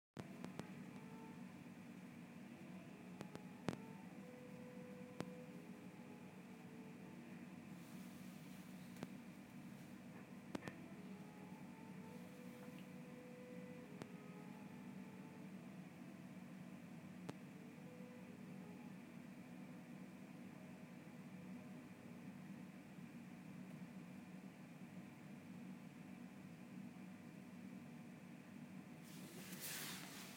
I appear to have a neighbour playing beautiful French Horn